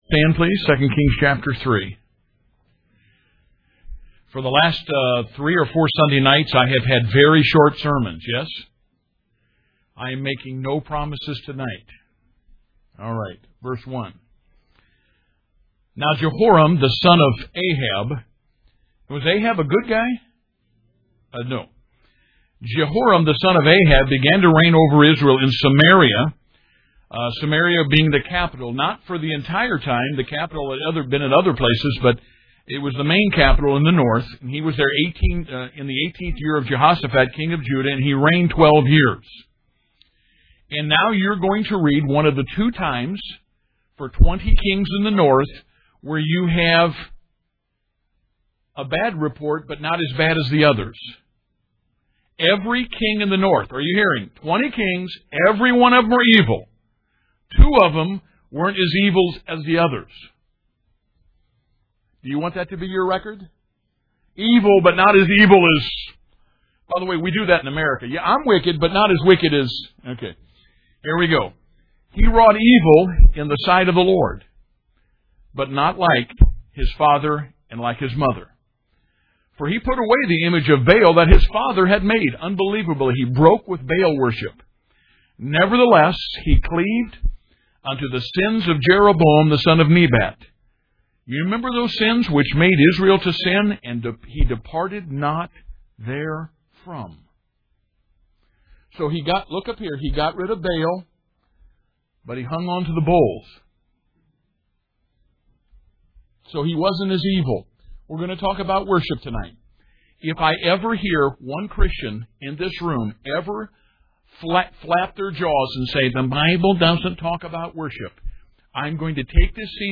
Series: 2 Kings Teaching and Preaching